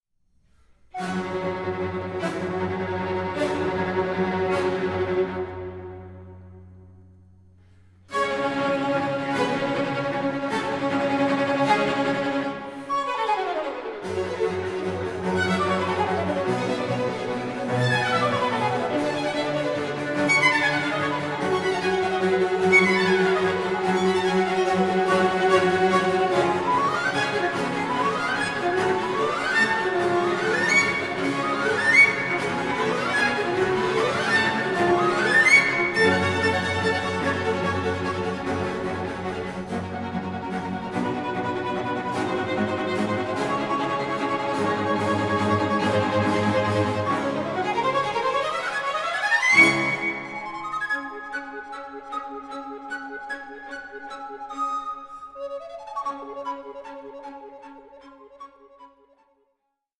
Recorder player
the recorder in place of the violin